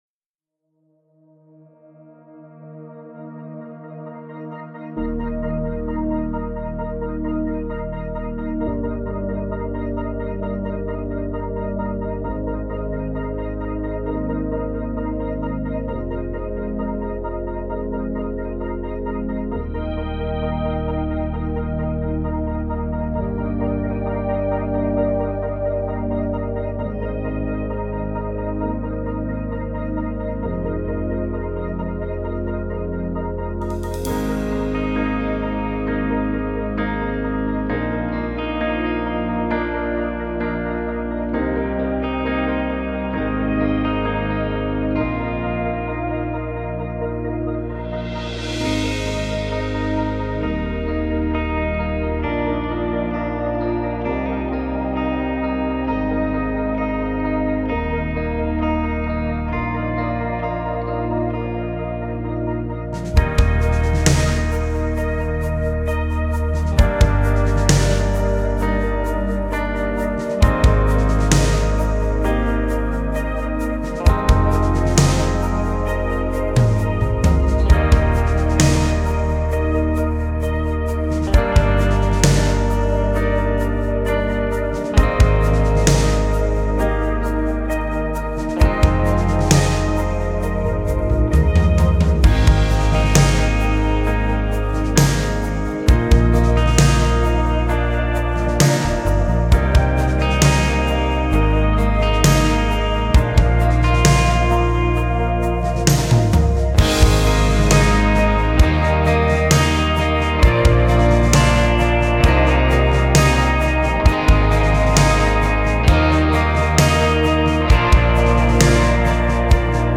Key Eb